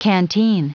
Prononciation du mot canteen en anglais (fichier audio)
Prononciation du mot : canteen
canteen.wav